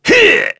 Crow-Vox_Attack3.wav